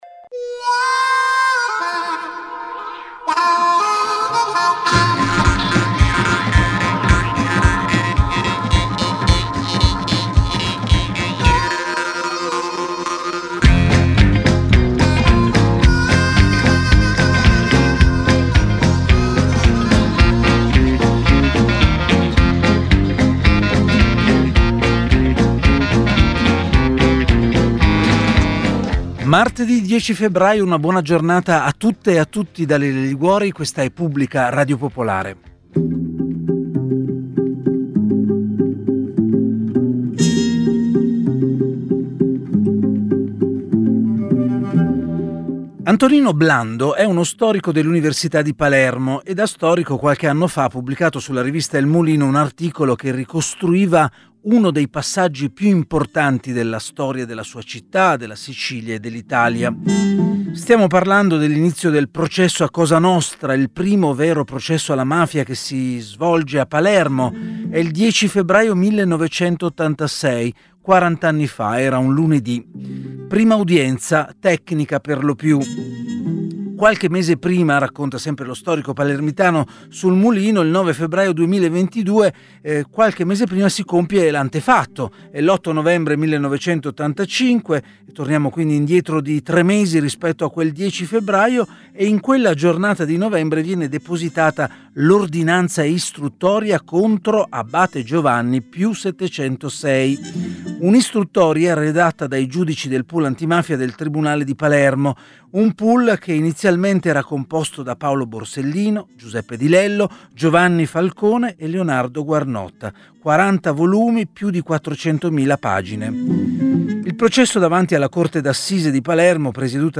Pubblica oggi ha ospitato Attilio Bolzoni, giornalista e scrittore, storico cronista di mafia.